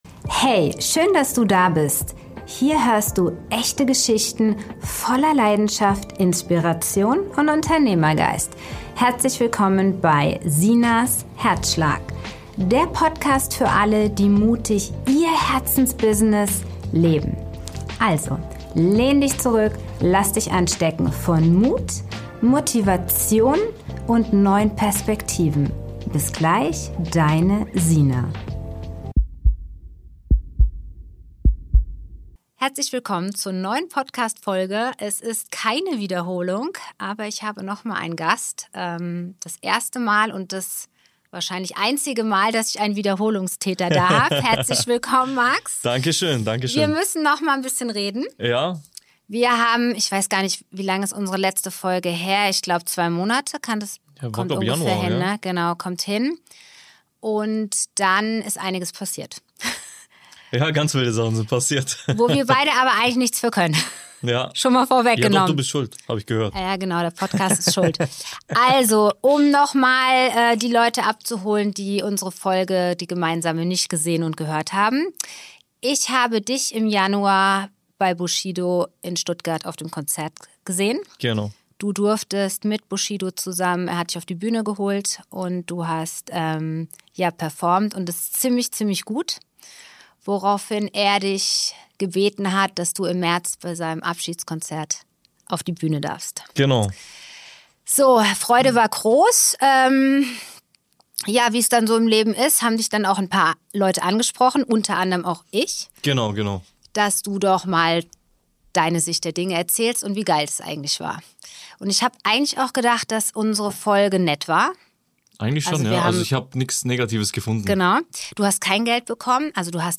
Eine ehrliche, direkte und ungeschnittene Unterhaltung.